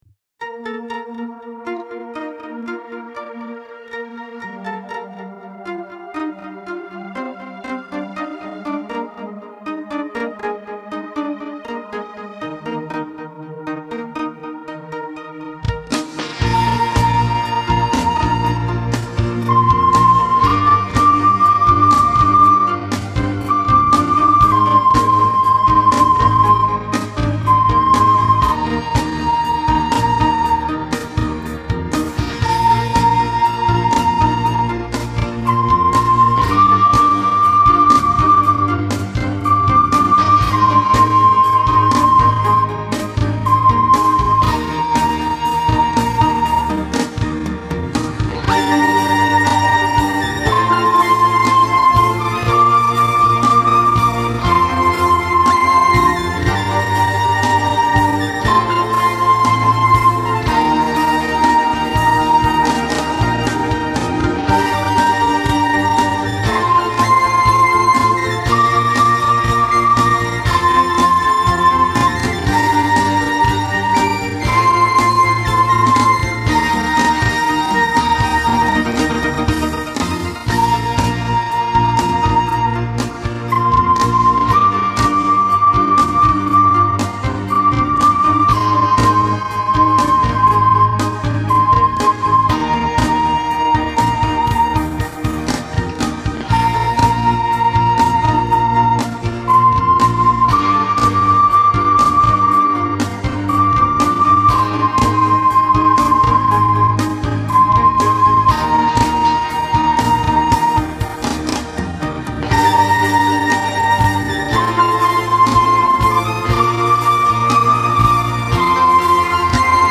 Three Star Cafe (slow).mp3